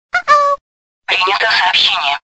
icq_prinjato_soobshenie.mp3